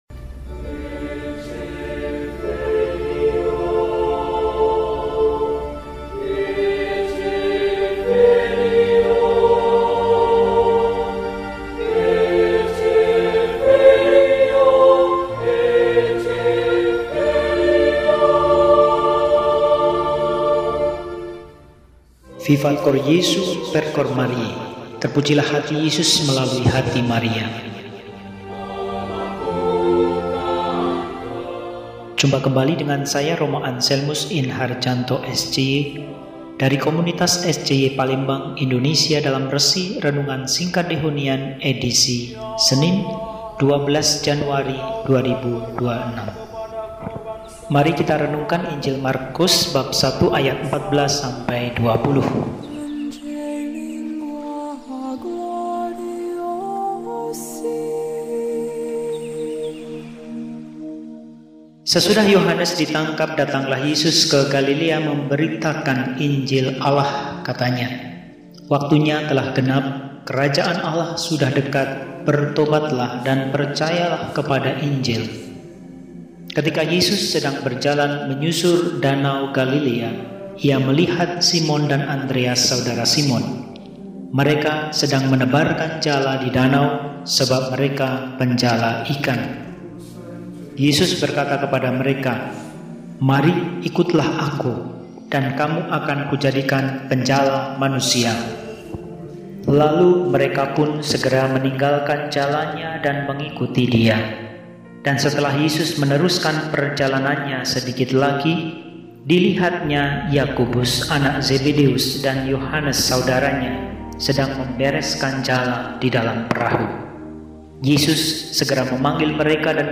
Senin, 12 Januari 2026 – Hari Biasa Pekan I – RESI (Renungan Singkat) DEHONIAN